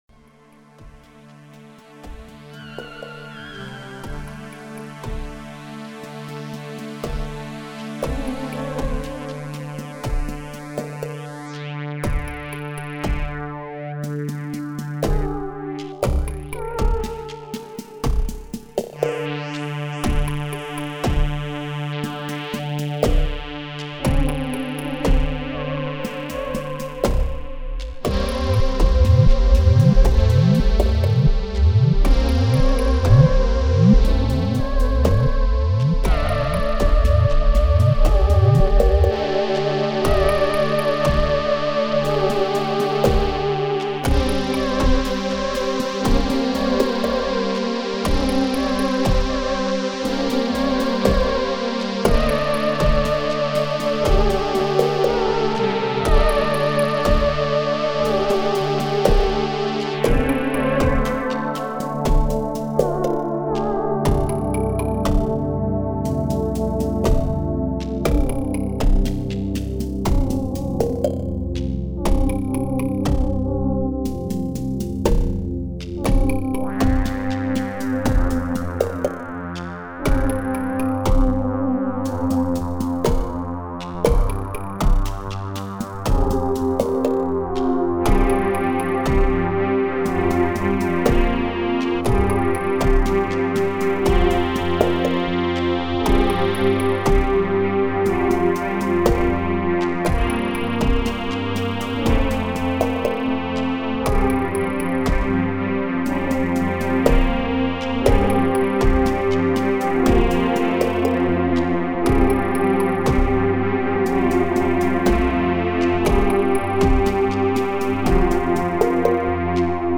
Sentimentale traurige Stimmung, wehmütige Synthie Klänge.
Reduzierte langsame Beats.
Tempo: 60 bpm / Datum: 19.06.2012